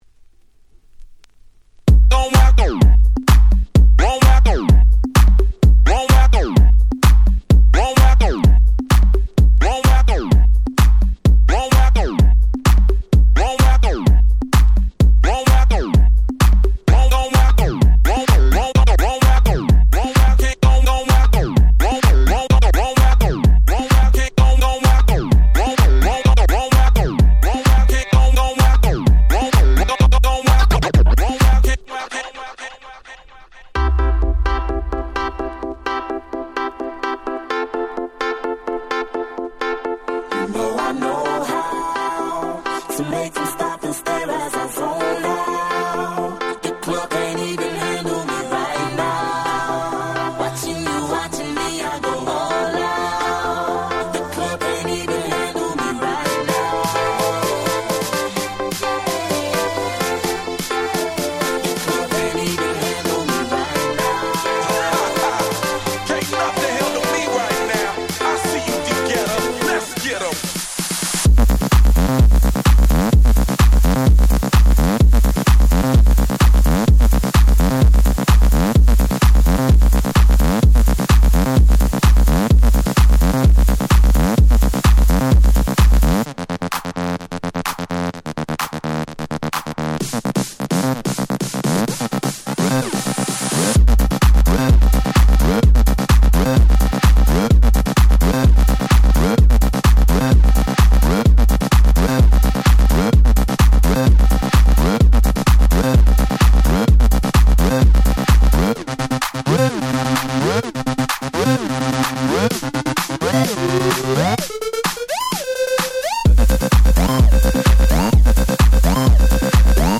11' Super Hit EDM / R&B / Hip Hop !!